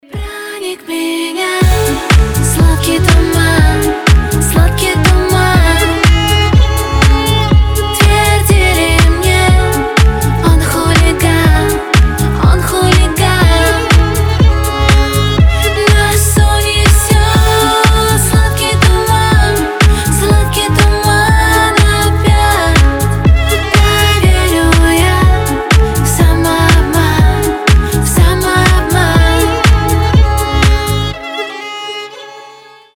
• Качество: 320, Stereo
женский голос
скрипка